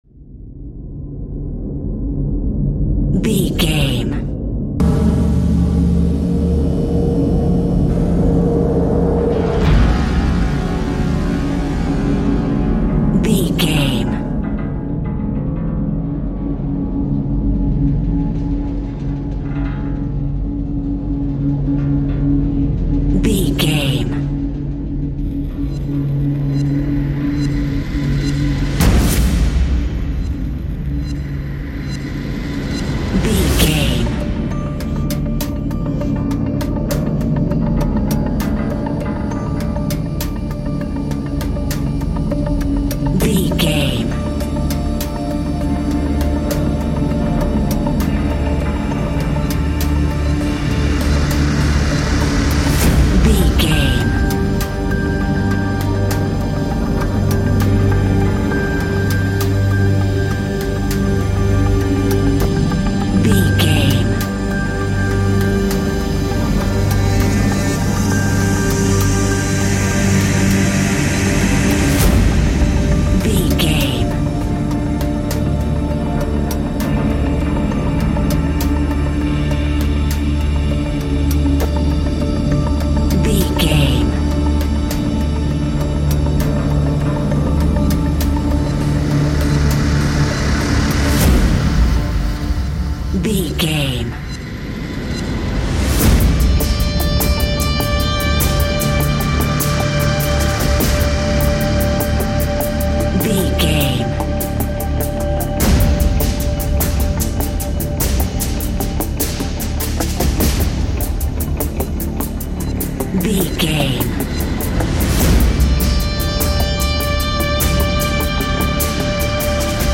Fast paced
In-crescendo
Ionian/Major
D♭
industrial
dark ambient
EBM
synths
Krautrock